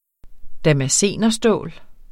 Udtale [ damaˈseˀnʌ- ]